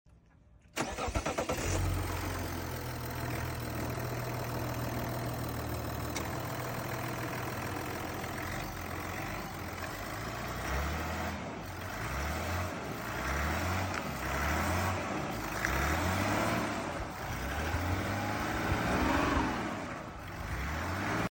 Name a better sound than 6 cylinder diesel engines⚓